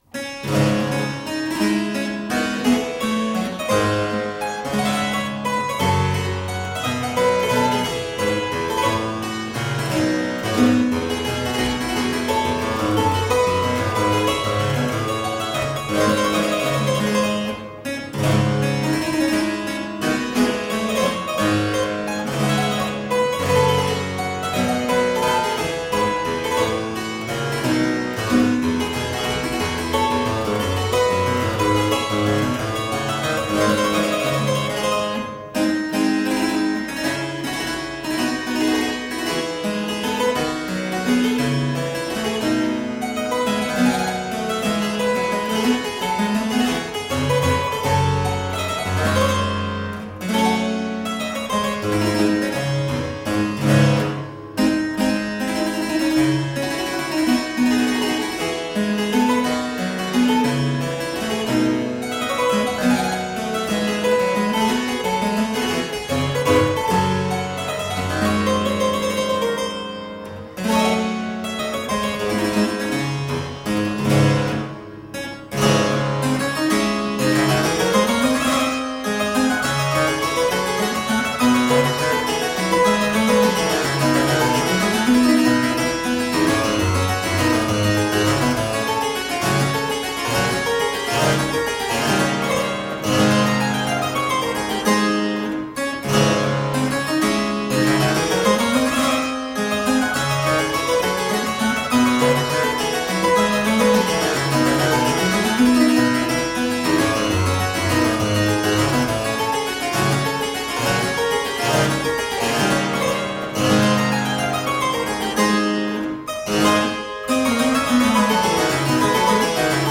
Solo harpsichord music.